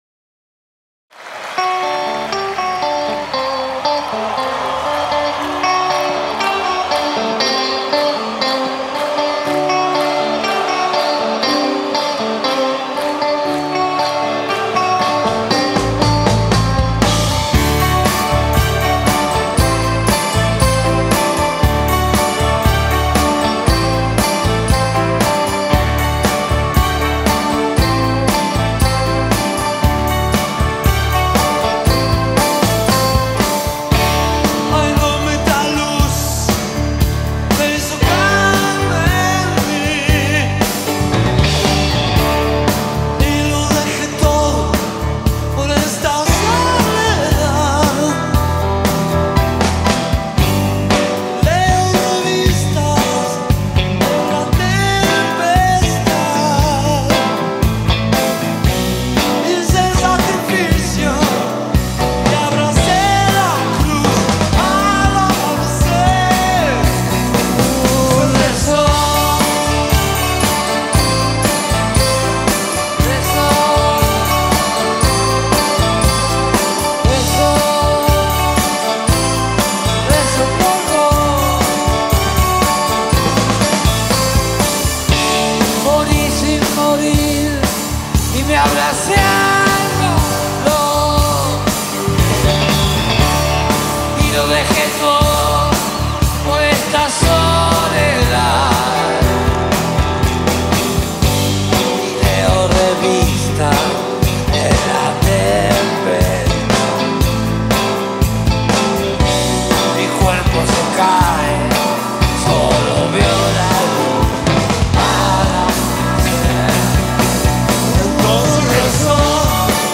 Carpeta: Rock argentino mp3